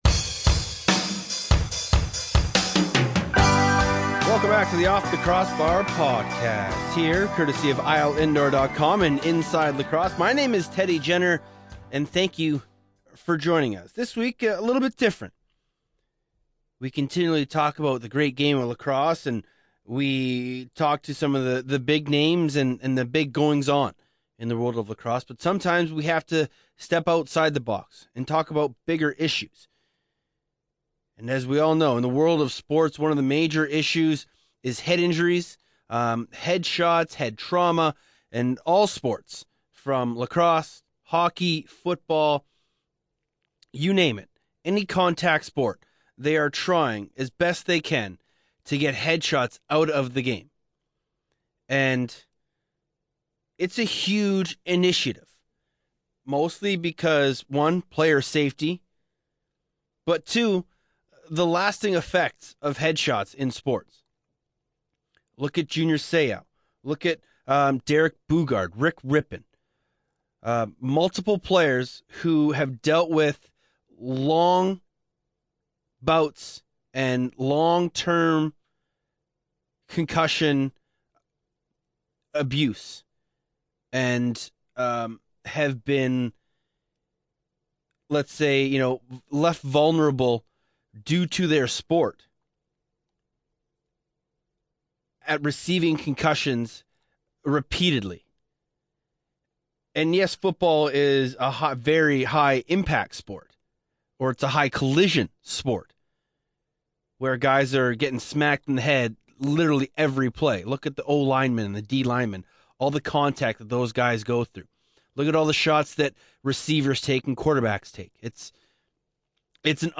This candid conversation confronts a controversial issue in today's contact sports.